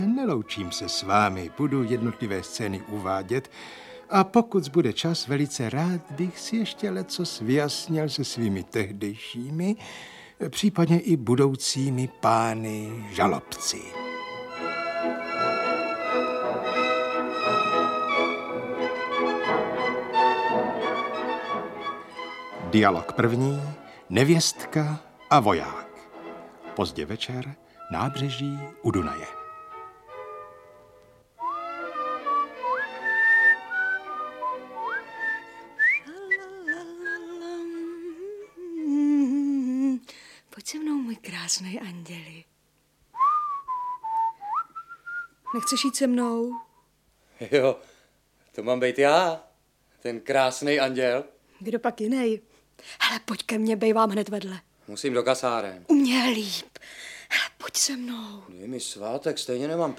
Audiobook
Read: Jaroslav Satoranský